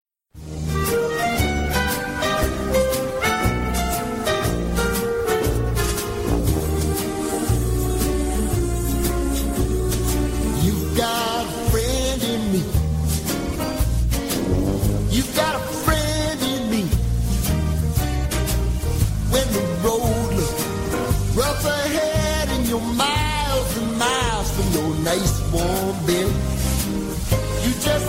Recording Location: CITR 101.9 FM, Vancouver
Comments: some of the volume needed to be raise, but you might hear some whoopiling noise during the broadcast.
Type: Weekly Program